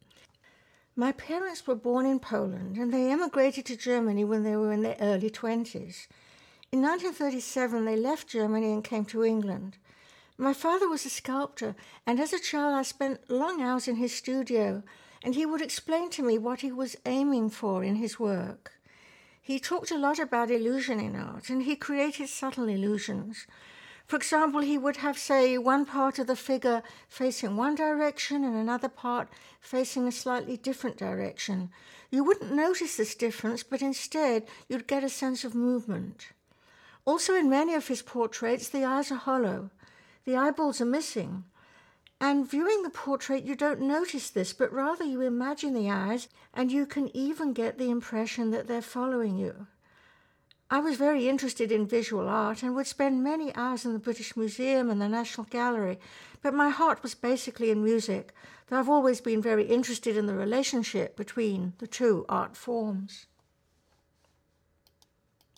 Dr. Deutsch introduces herself in this first excerpt: